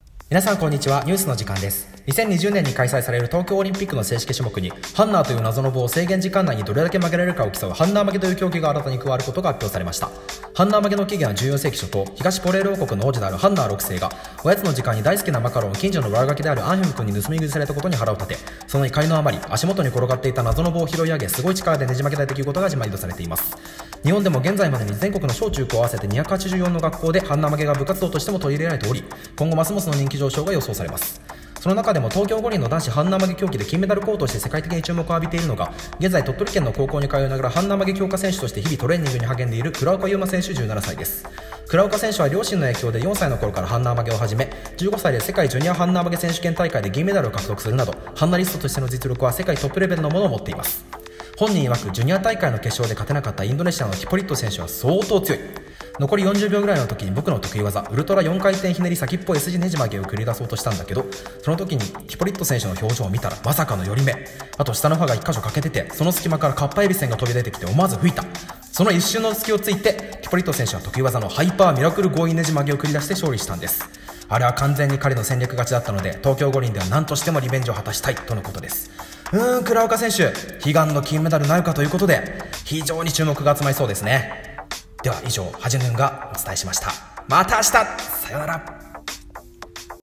ニュース原稿朗読2